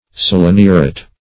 seleniuret - definition of seleniuret - synonyms, pronunciation, spelling from Free Dictionary Search Result for " seleniuret" : The Collaborative International Dictionary of English v.0.48: Seleniuret \Sel`e*ni"u*ret\, n. (Chem.)
seleniuret.mp3